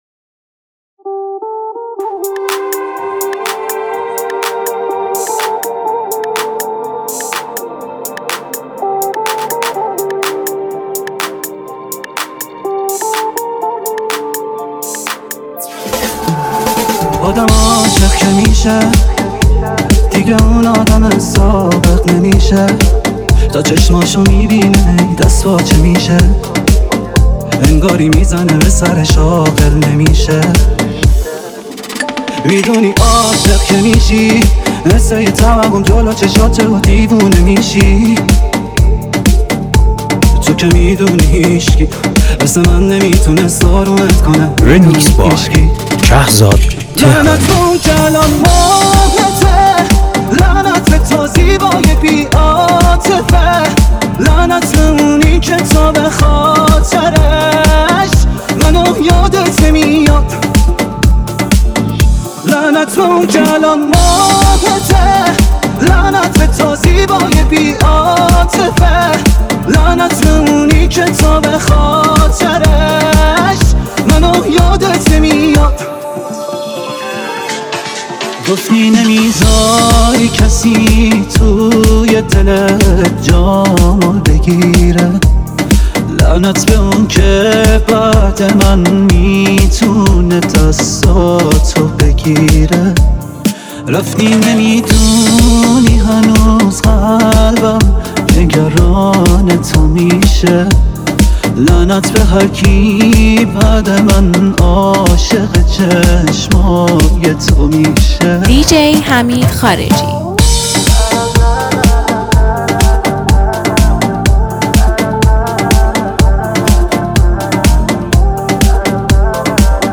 این میکس پرانرژی